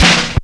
FILLDRUM08-R.wav